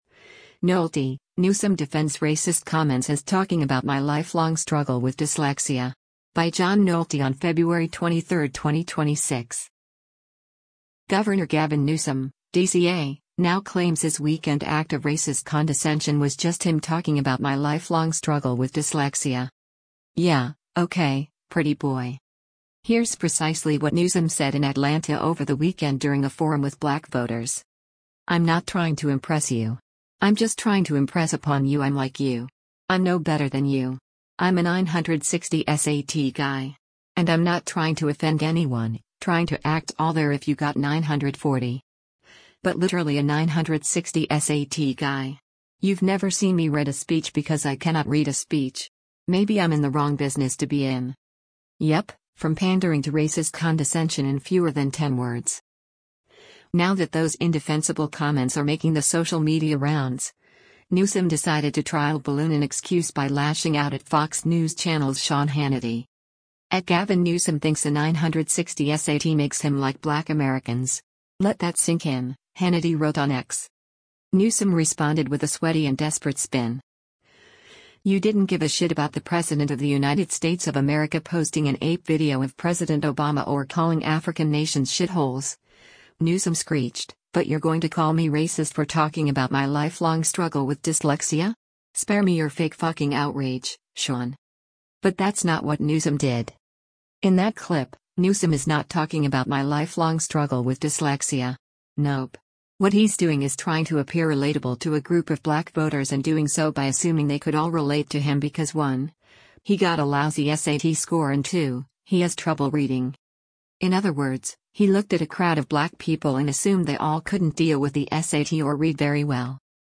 Here’s precisely what Newsom said in Atlanta over the weekend during a forum with black voters: